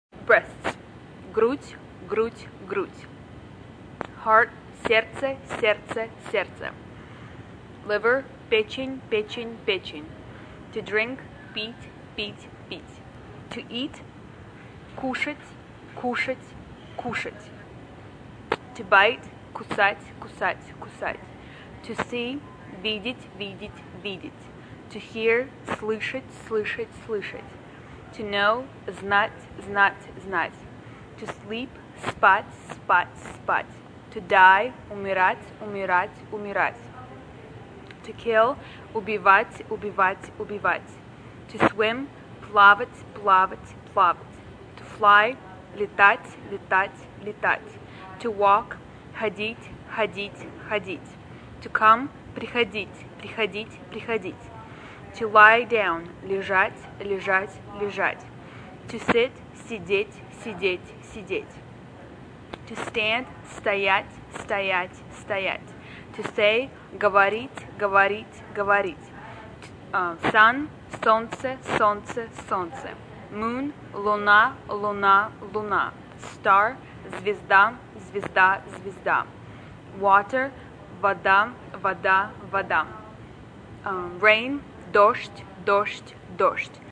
Each word is repeated three times.